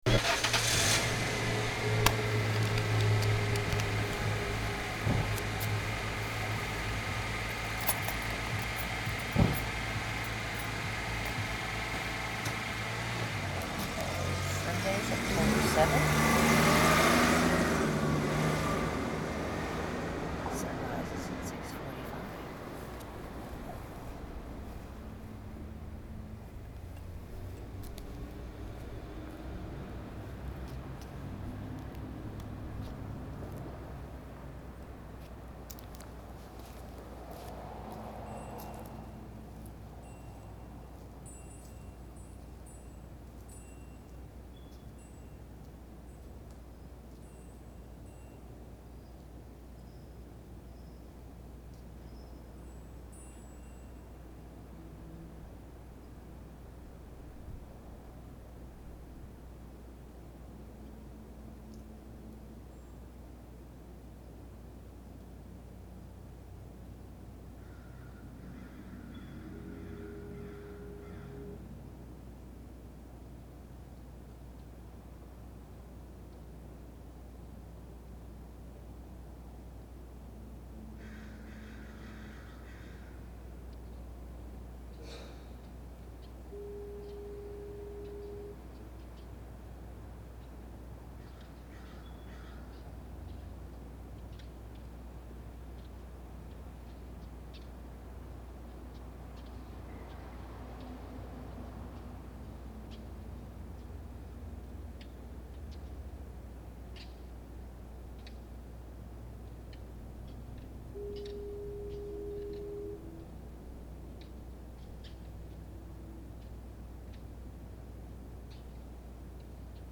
There was a lot of activity outside this morning – especially for a Sunday.